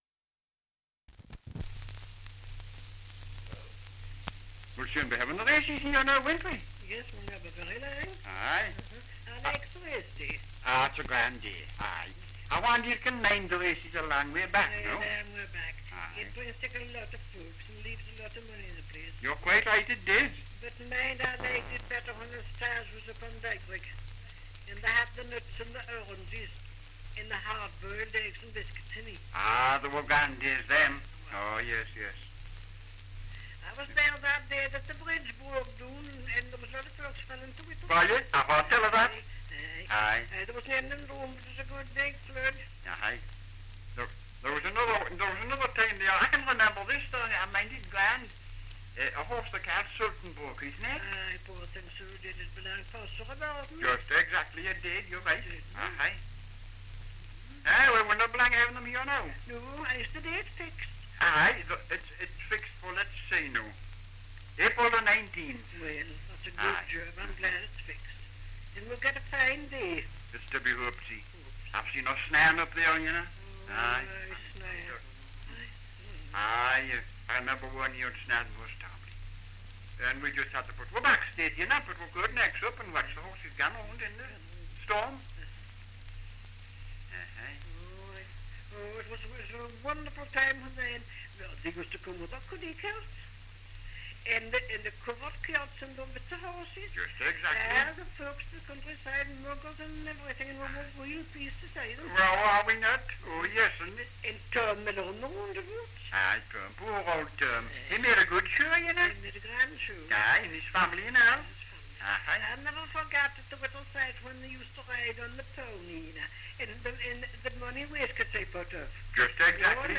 Dialect recording in Rothbury, Northumberland
78 r.p.m., cellulose nitrate on aluminium